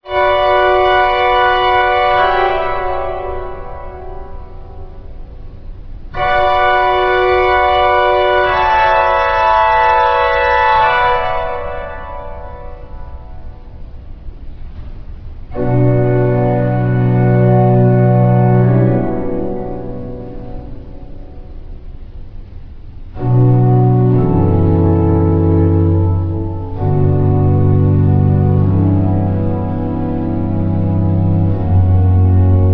boy soprano
organ.